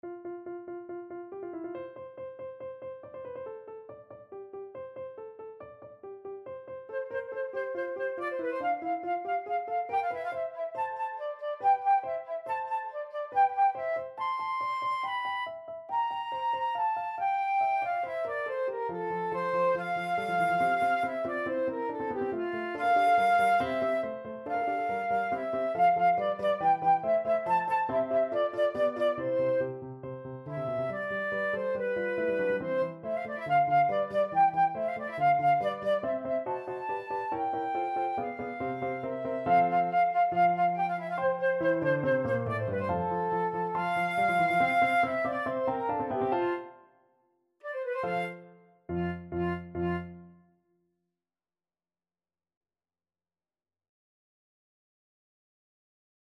= 140 Allegro (View more music marked Allegro)
F major (Sounding Pitch) (View more F major Music for Flute )
4/4 (View more 4/4 Music)
Flute  (View more Intermediate Flute Music)
Classical (View more Classical Flute Music)